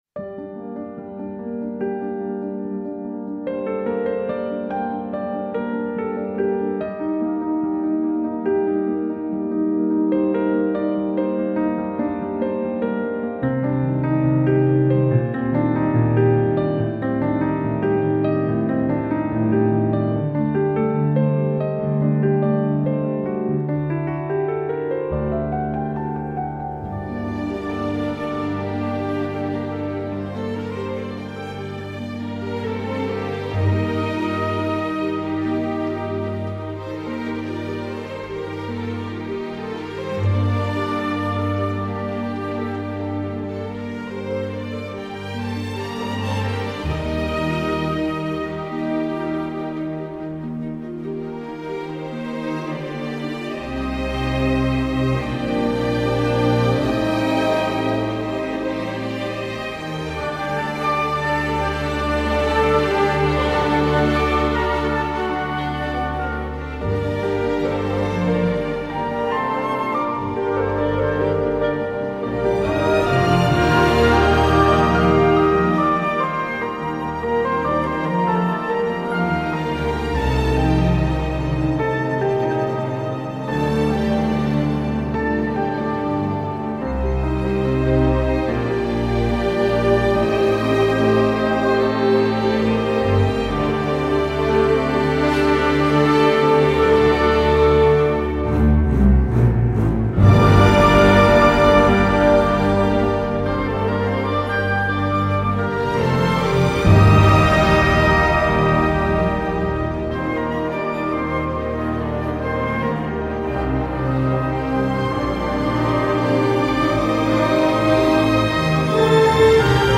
smooth and engaging tune